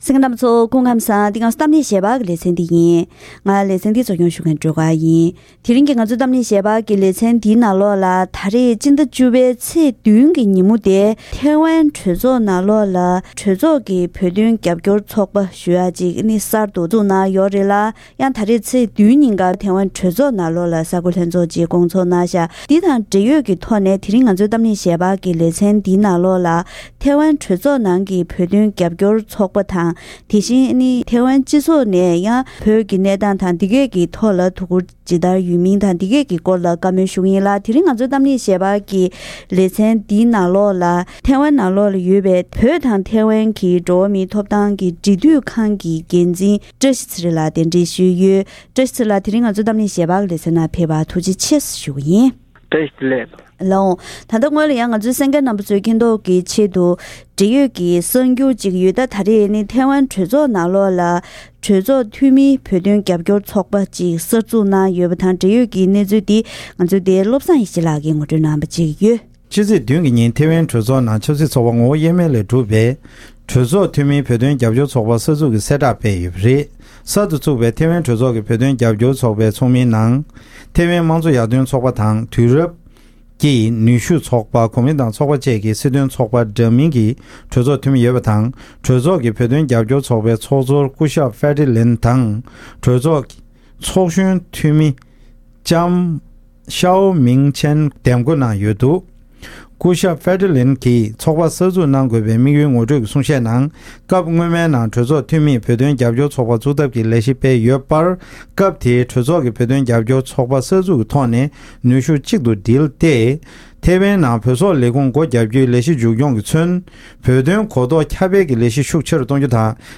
ད་རིང་གི་གཏམ་གླེང་ཞལ་པར་ལེ་ཚན་ནང་ཐེ་ཝན་གྲོས་ཚོགས་སུ་གྲོས་ཚོགས་འཐུས་མིའི་བོད་དོན་རྒྱབ་སྐྱོར་ཚོགས་པ་གསར་བཙུགས་གནང་བ་དེས་བོད་དོན་ཐད་དང་ཐེ་ཝན་ནང་བོད་དོན་ཐོག་དོ་འཁུར་རྒྱབ་སྐྱོར་གནང་ཕྱོགས་ཐོག་ཤུགས་རྐྱེན་གང་འདྲ་ཡོང་མིན་སོགས་ཀྱི་གནད་དོན་སྐོར་ལ་འབྲེལ་ཡོད་མི་སྣ་དང་ལྷན་བཀའ་མོལ་ཞུས་པ་ཞིག་གསན་རོགས་གནང་།